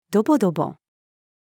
ドボドボ-female.mp3